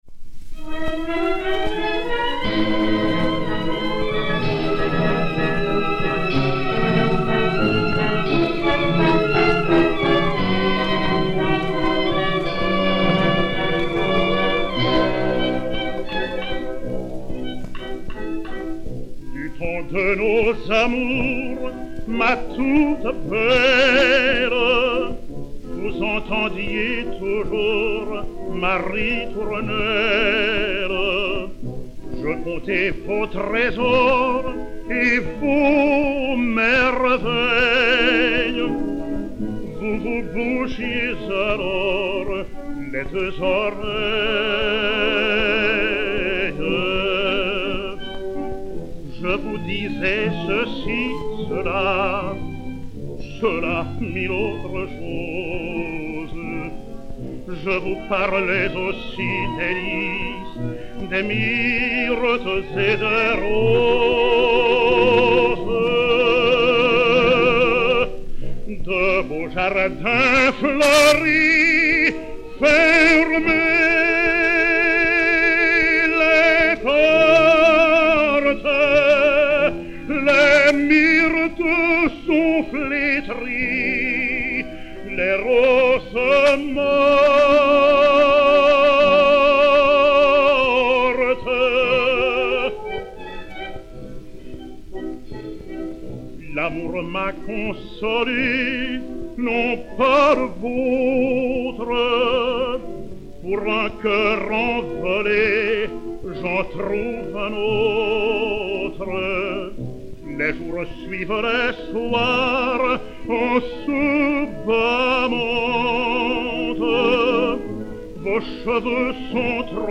(les), aubade, poésie de Gustave Nadaud (1872) => partition
Orchestre